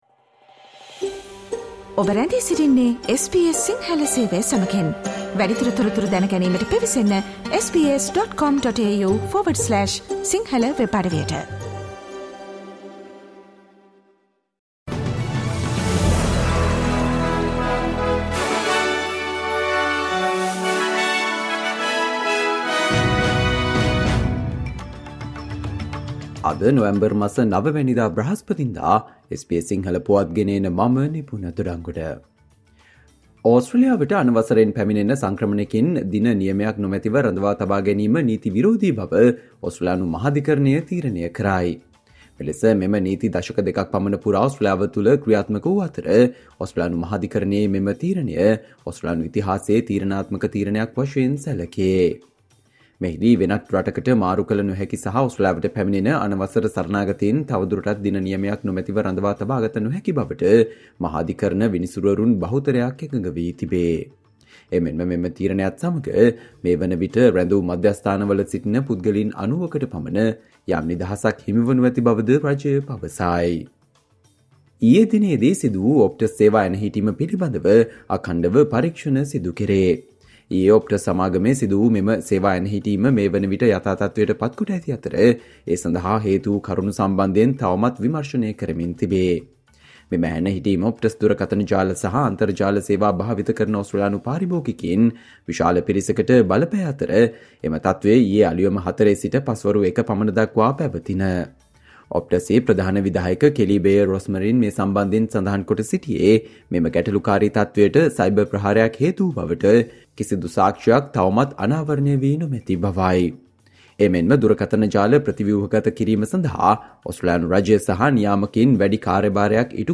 Australia news in Sinhala, foreign and sports news in brief - listen Sinhala Radio News Flash on Thursday 09 November 2023.